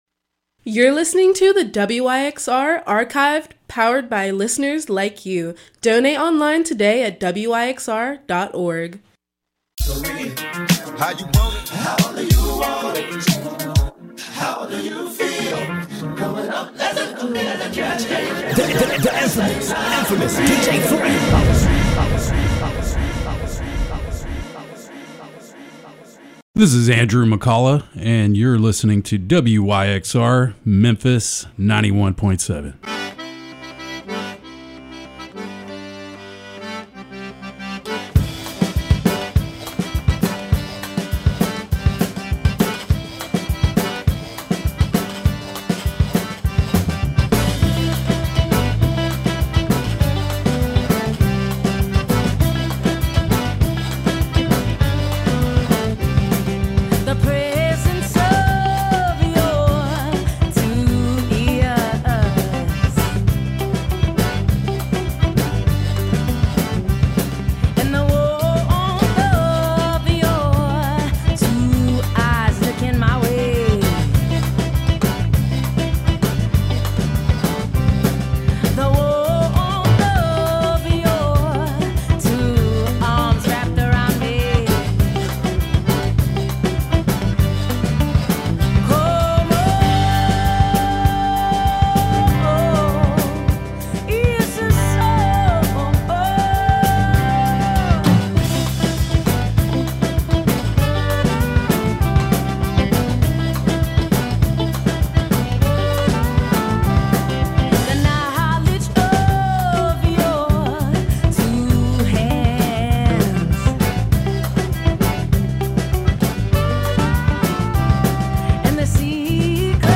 Dance World Soul
breakbeat, bachata, dembow